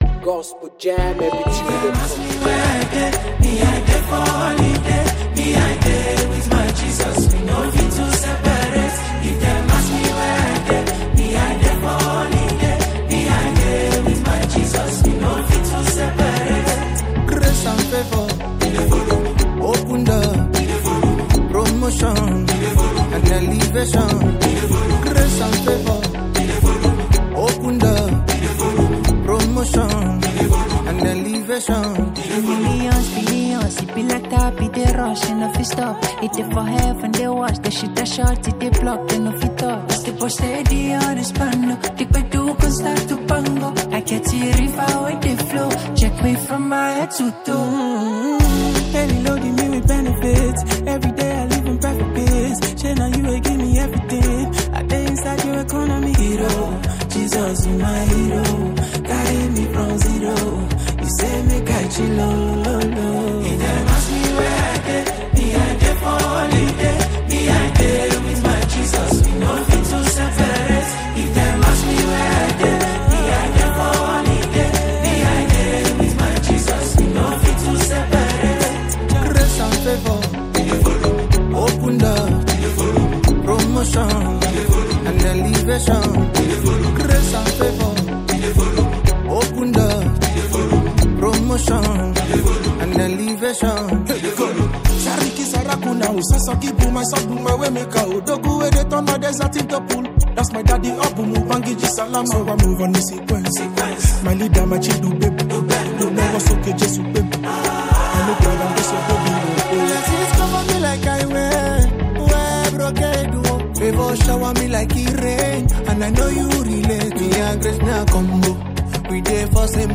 joyful and energetic gospel tune
vibrant Afro-gospel sound
infectious rhythm